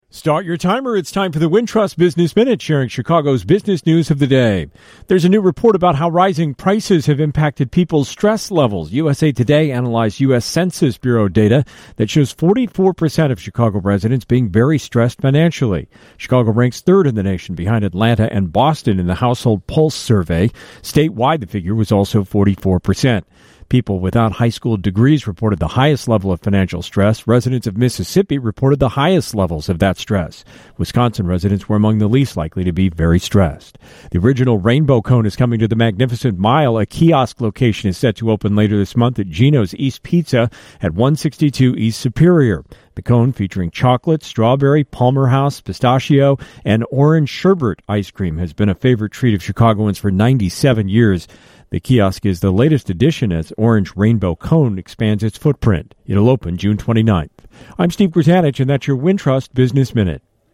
Category: Business